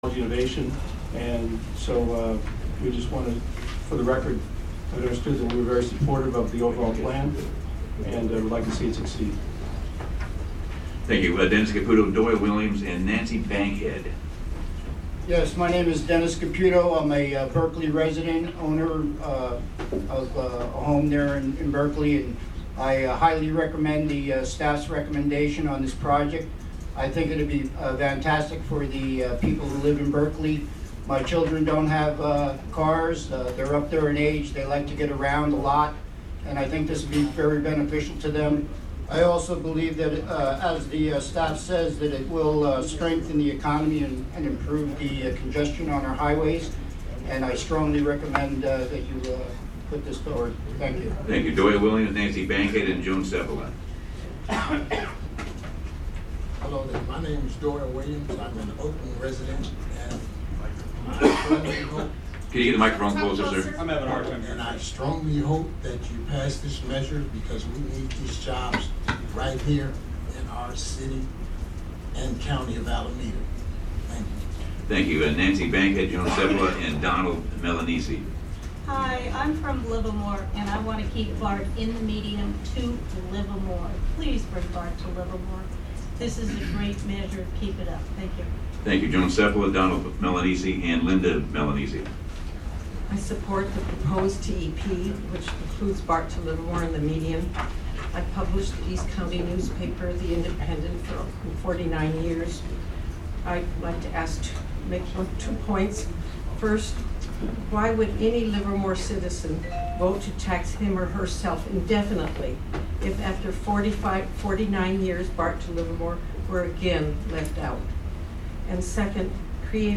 Location Alameda CTC Offices 1333 Broadway, Suite 300 Oakland, CA, 94612
Recordings from Steering Committee Meetings: